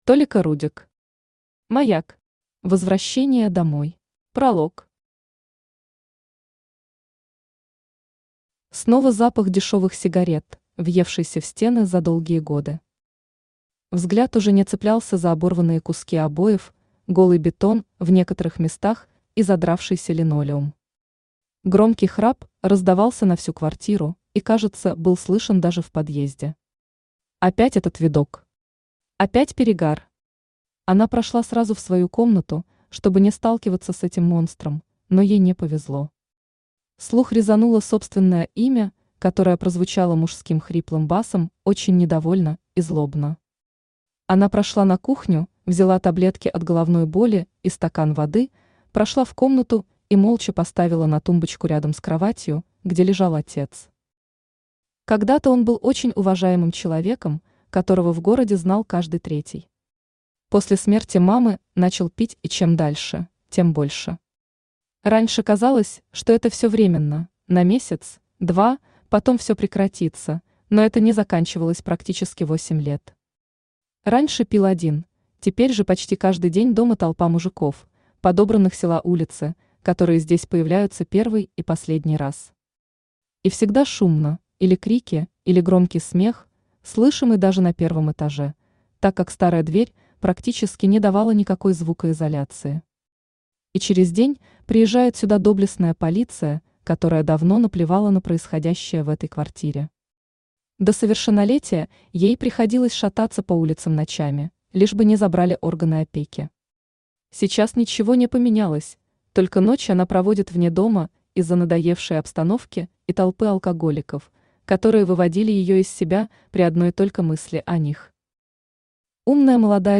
Аудиокнига Маяк. Возвращение домой | Библиотека аудиокниг
Aудиокнига Маяк. Возвращение домой Автор Толика Рудик Читает аудиокнигу Авточтец ЛитРес.